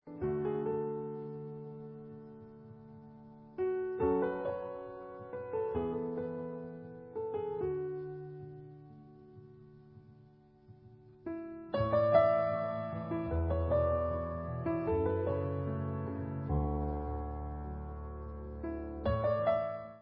Klasika